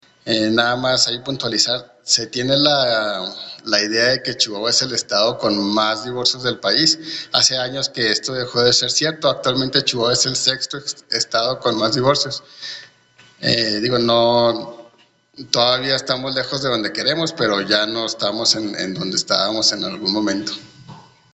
AUDIO; RAFAEL CORRAL VALVERDE, DIRECTOR DEL REGISTRO CIVIL DE CHIHUAHUA